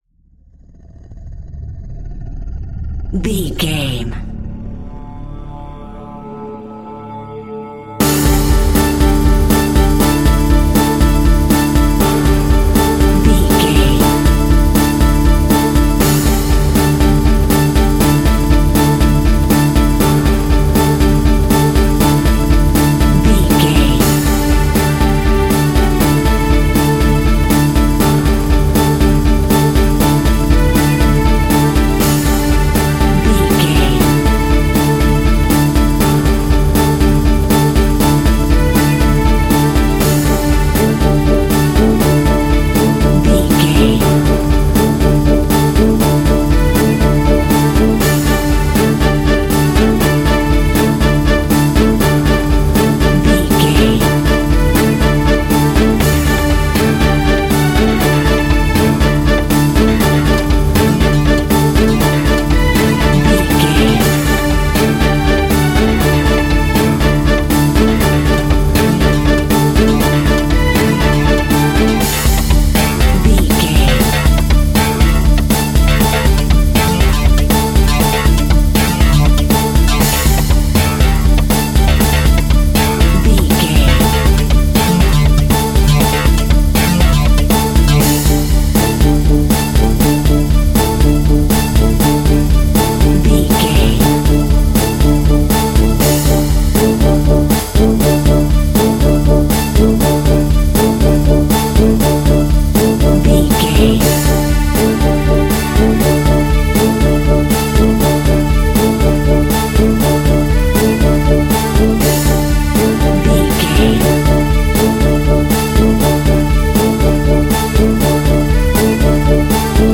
Energetic Sport Pop.
Ionian/Major
breakbeat
pumped up rock
power pop rock
electronic
synth lead
synth bass